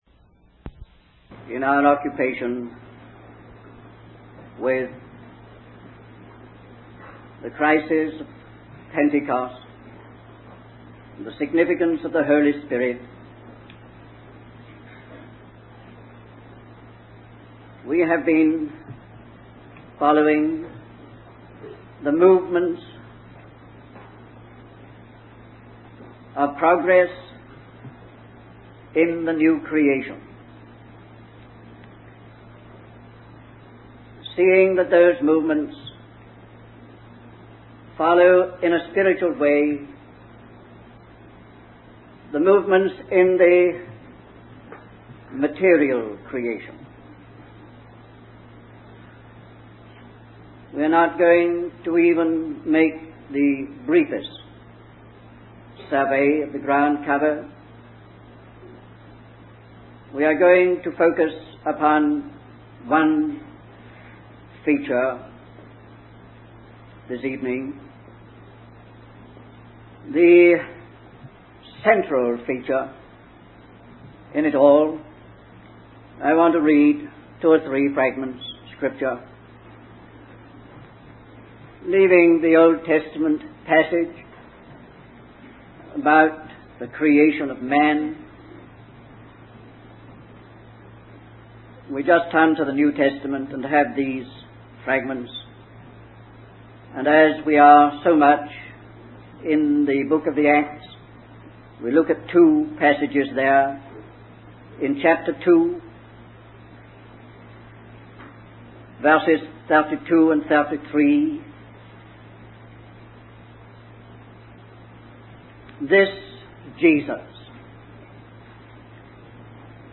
In this sermon, the speaker focuses on the significance of the Holy Spirit in the new creation.